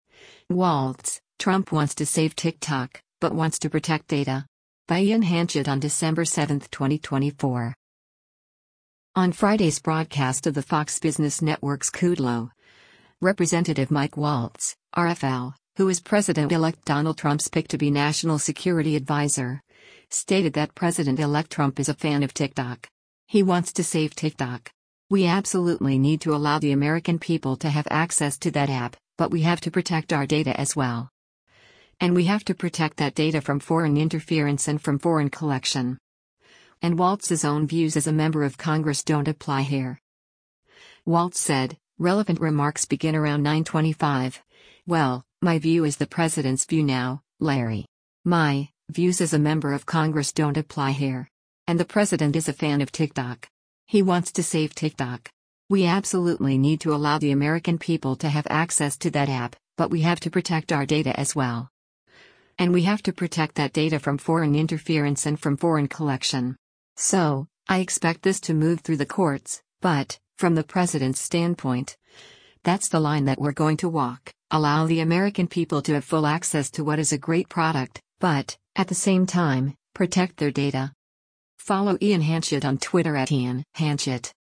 On Friday’s broadcast of the Fox Business Network’s “Kudlow,” Rep. Mike Waltz (R-FL), who is President-Elect Donald Trump’s pick to be national security adviser, stated that President-Elect Trump “is a fan of TikTok. He wants to save TikTok. We absolutely need to allow the American people to have access to that app, but we have to protect our data as well.